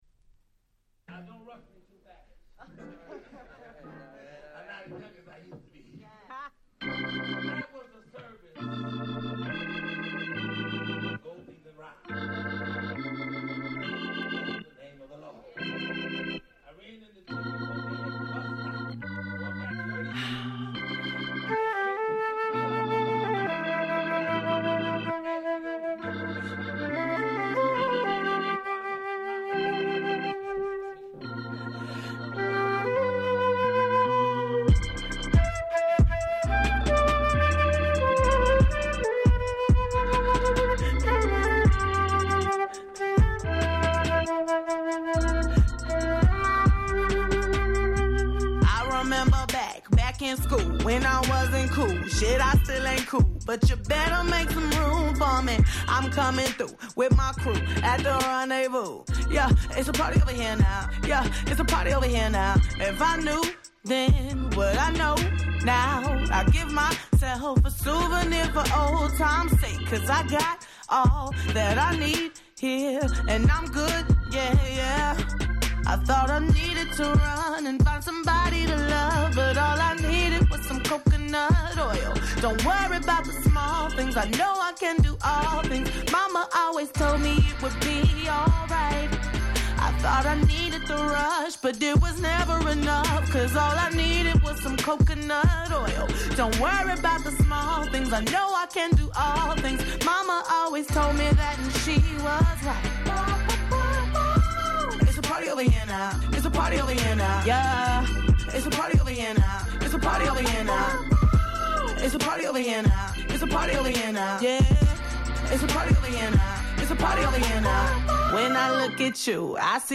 19' Smash Hit R&B !!